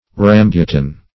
Rambutan \Ram*bu"tan\ (r[a^]m*b[=oo]"t[a^]n), n. [Malay